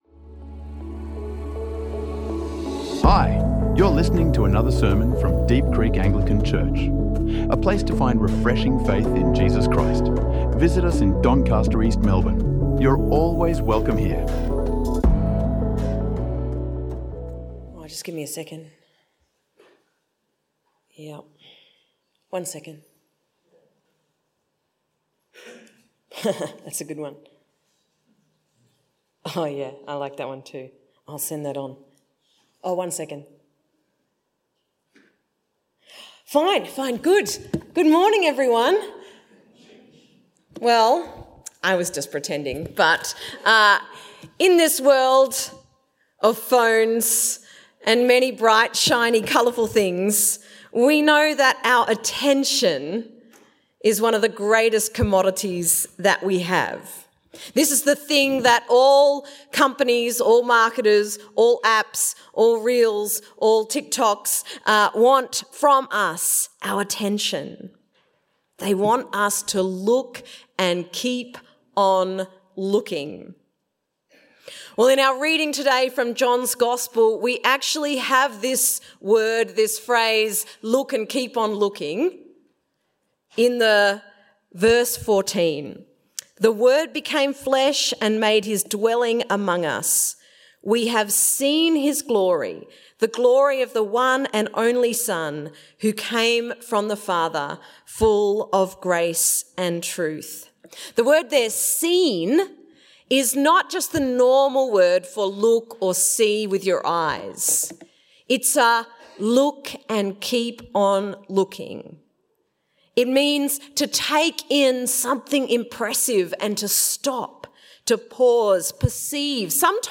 Beholding the Glory, A Christmas Day Sermon | Deep Creek Anglican Church
A sermon on beholding Jesus, the Light who breaks through darkness and rewires our hearts for connection and hope.